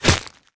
bullet_impact_ice_04.wav